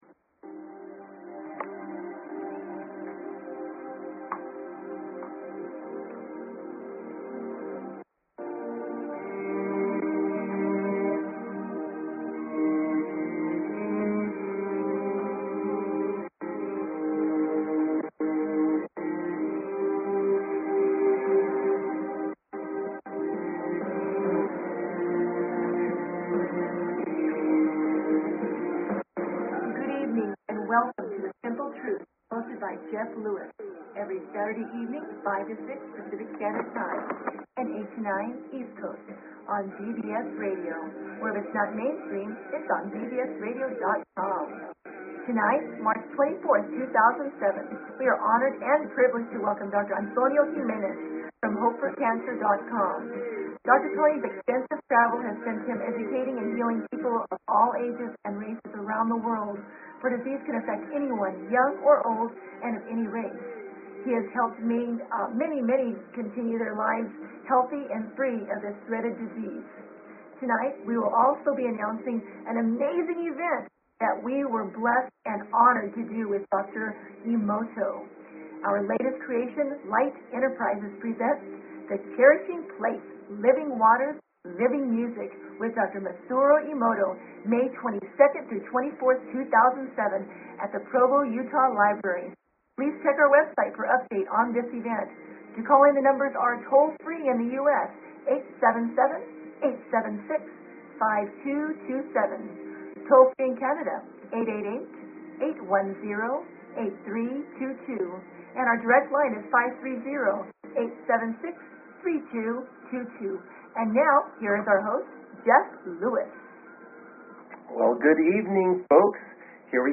Courtesy of BBS Radio
The Simple Truth will be focused around cutting-edge nutrition, health and healing products and modalities from around the world. We will be interviewing the worlds greatest health and nutrition experts regarding the latest research and developments in health and nutritional technology.